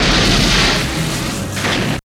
51 WIND   -R.wav